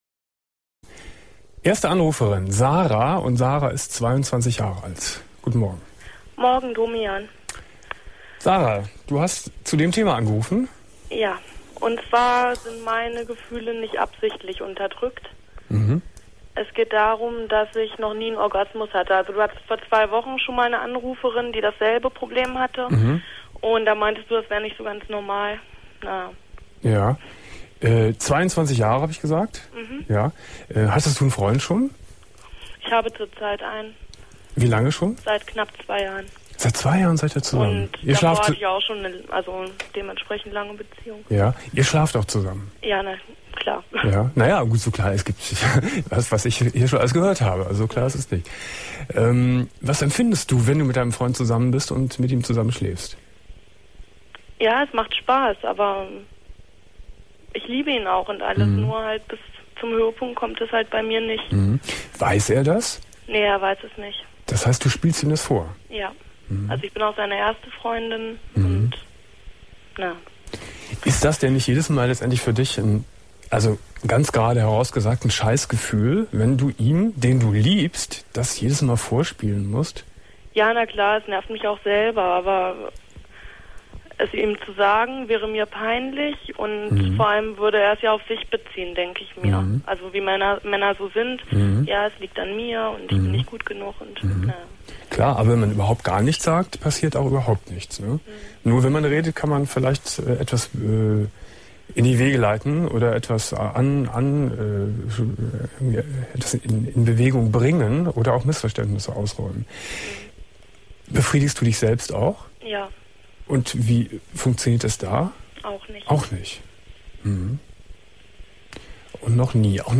21.02.2001 Domian Thema: Unterdrückte Gefühle ~ Domian Talkradio - Das Archiv Podcast